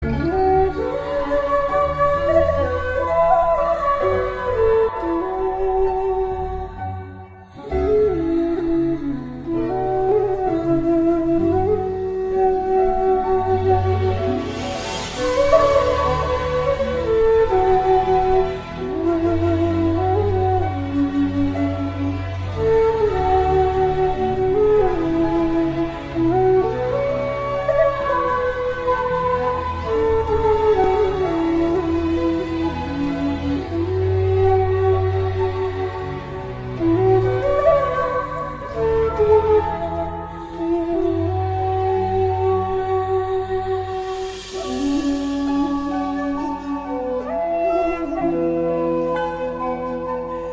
Sáo Trúc
Không Lời